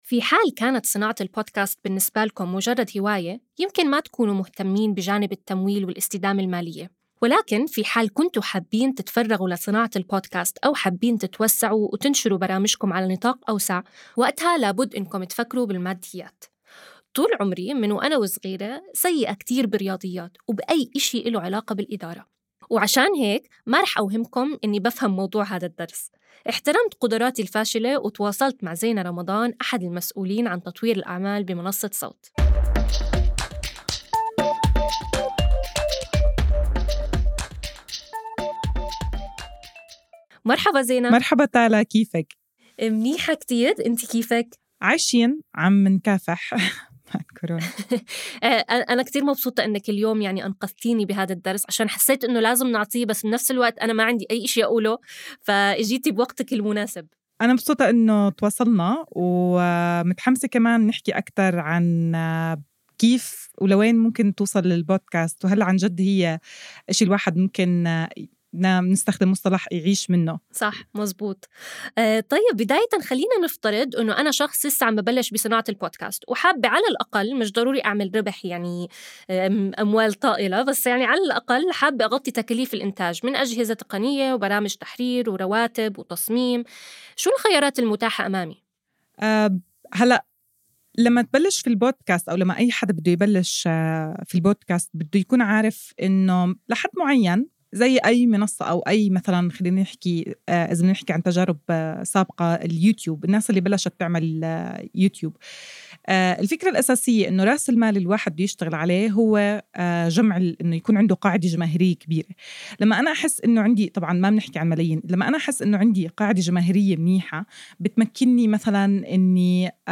ابتداءً من الحلقة ١٤ وحتى نهاية المساق لن تجدوا تفريغ لهذه الحلقات حيث ستكون الحلقات حوارية وتكمن قيمتها في الاستماع لها.